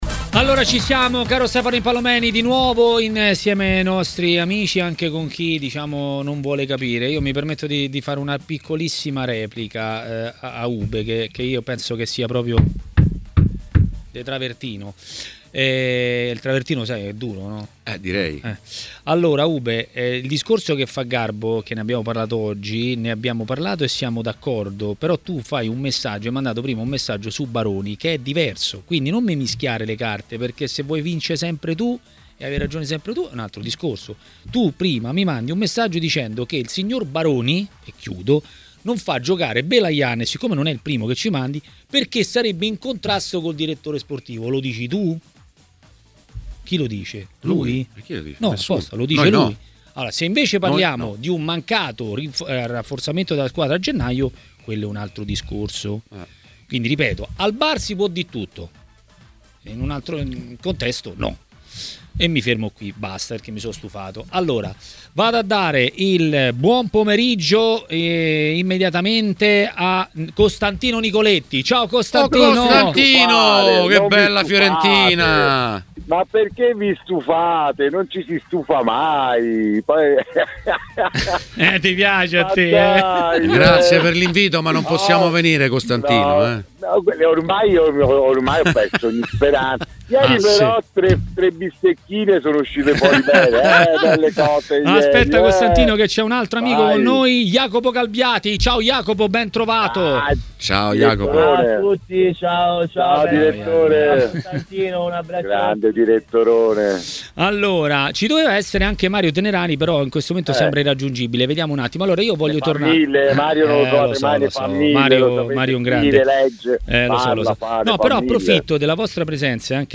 © registrazione di TMW Radio Facebook twitter Altre notizie